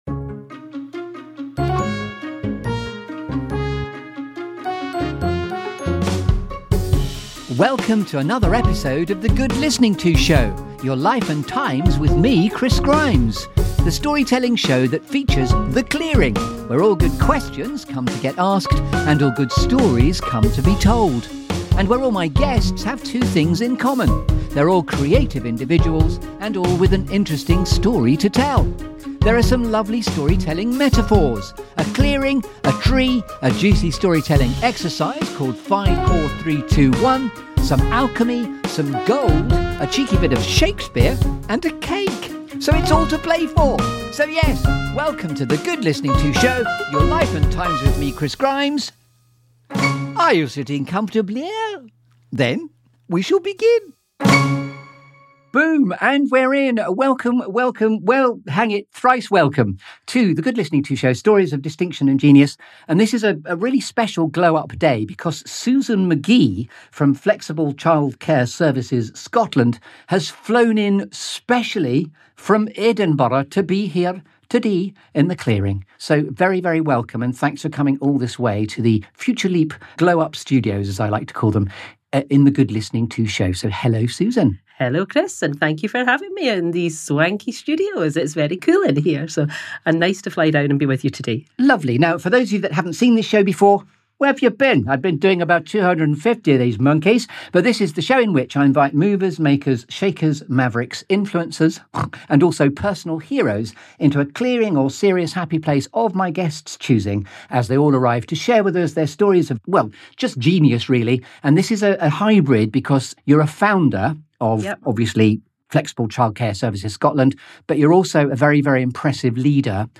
This feel-good Storytelling Show that brings you ‘The Clearing’.